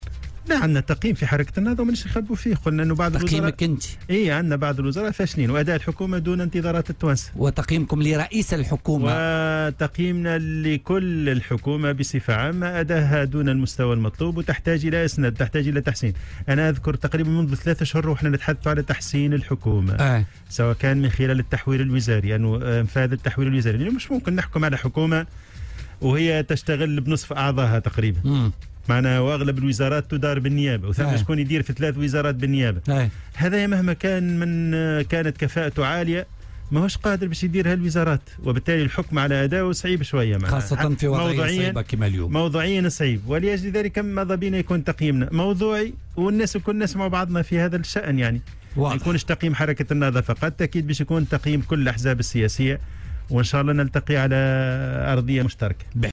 وأضاف في مداخلة له اليوم في برنامج "بوليتيكا" أن الحكومة تحتاج إلى إسناد من خلال تحوير وزاري لتحسين عملها.